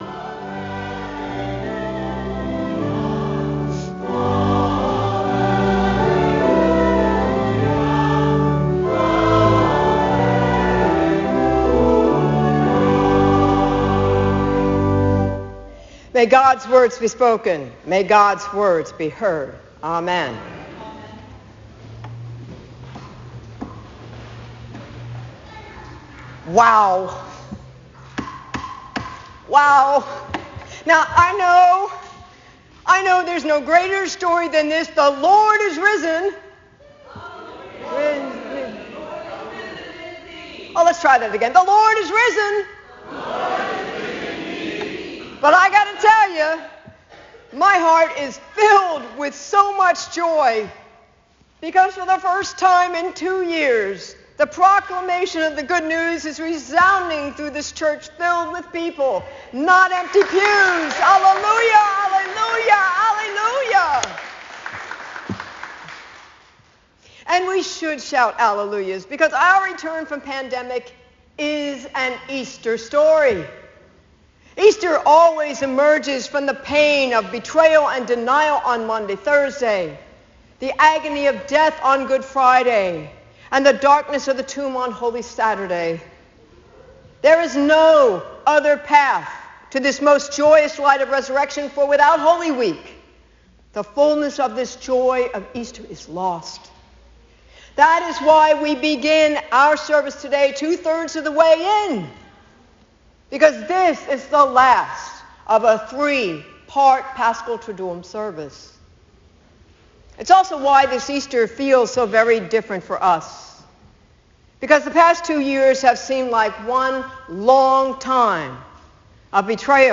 [Note: The initial lines in the sermon were added from the pulpit and inserted afterward] Wow!